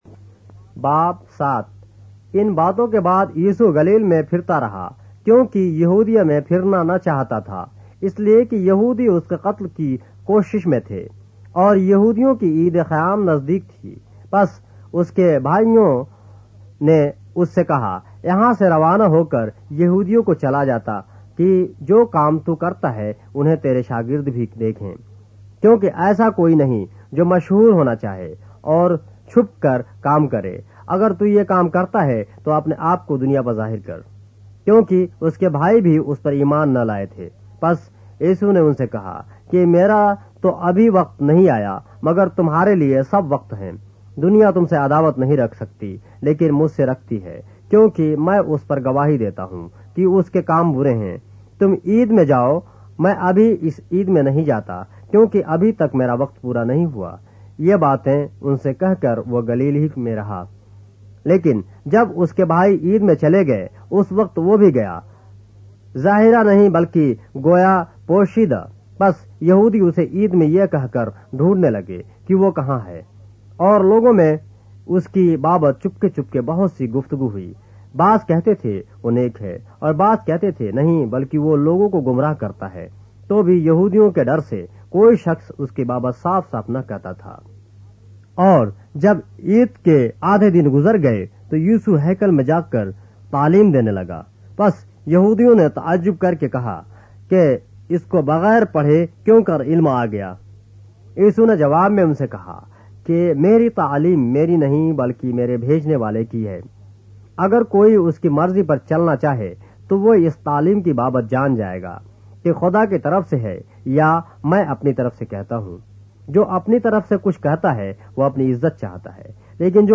اردو بائبل کے باب - آڈیو روایت کے ساتھ - John, chapter 7 of the Holy Bible in Urdu